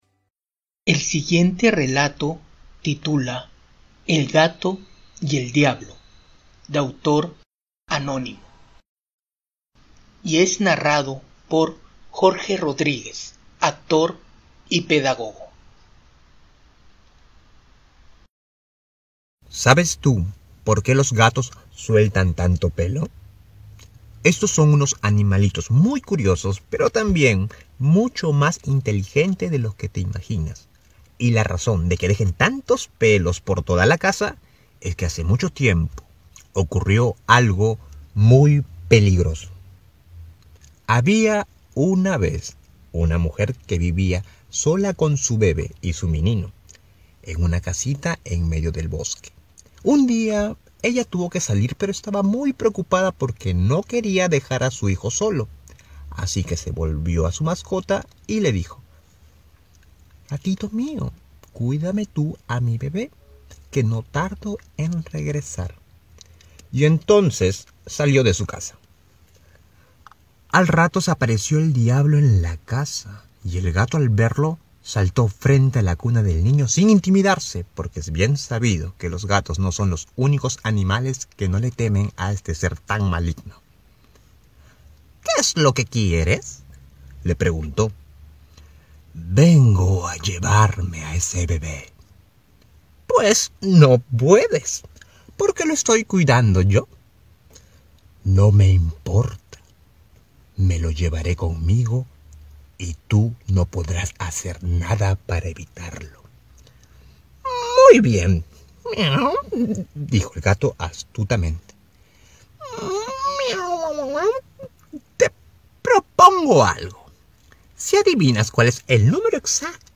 leyenda-el-gato-y-el-diablo-audiorelato.mp3